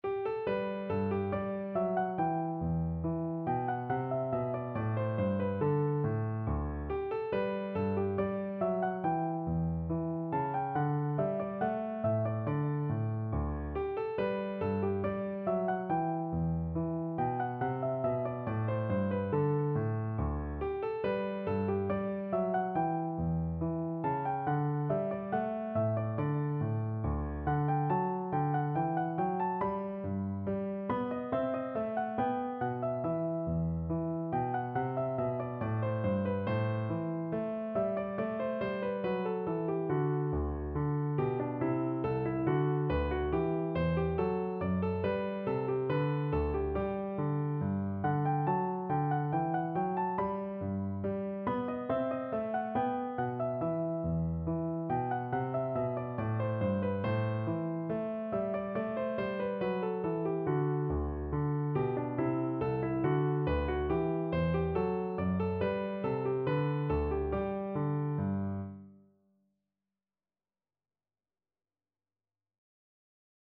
No parts available for this pieces as it is for solo piano.
4/4 (View more 4/4 Music)
G major (Sounding Pitch) (View more G major Music for Piano )
Piano  (View more Easy Piano Music)
Classical (View more Classical Piano Music)
handel_gavotte_hwv491_PNO.mp3